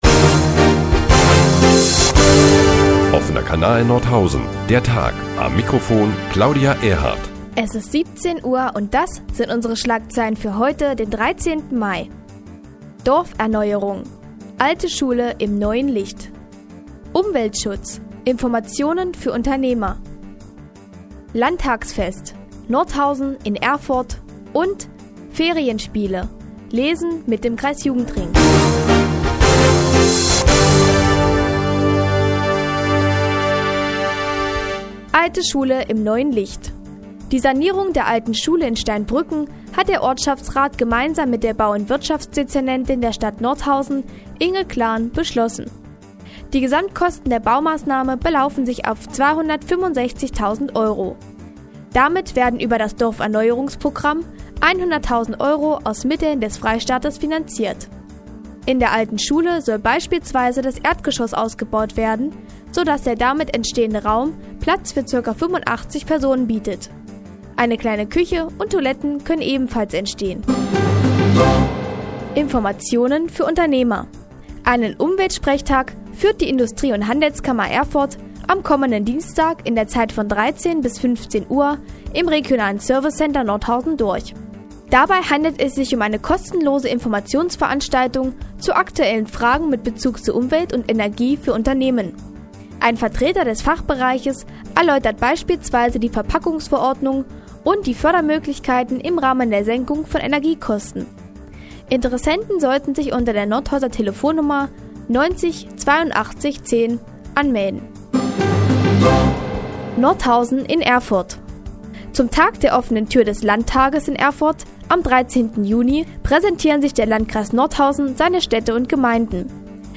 Die tägliche Nachrichtensendung des OKN ist nun auch in der nnz zu hören. Heute unter anderem mit einer Informationsveranstaltung für Unternehmer und Lesen mit dem Kreisjugendring in den Sommerferien.